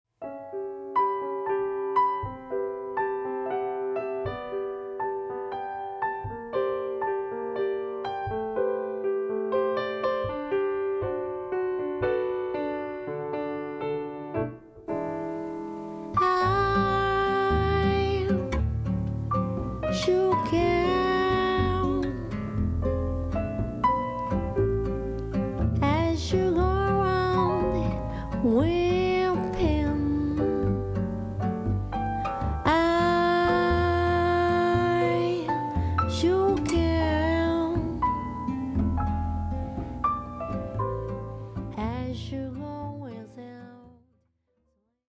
guitar / vocal
bass
piano / rhodes / melodica